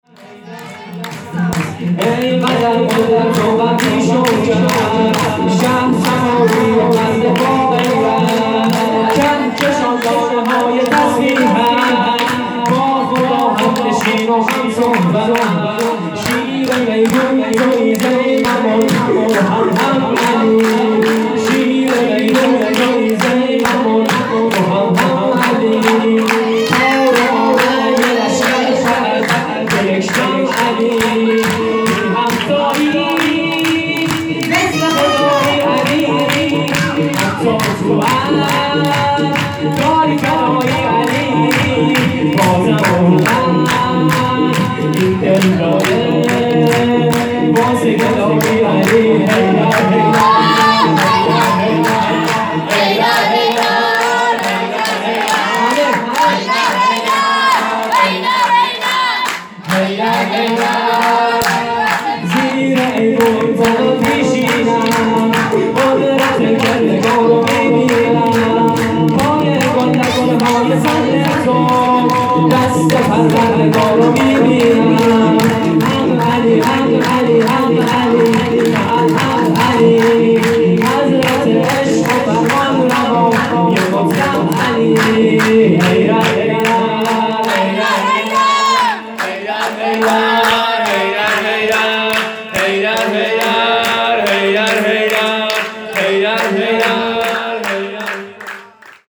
خیمه گاه - شجره طیبه صالحین - زیر ایوان طلات _ شور _ عید غدیر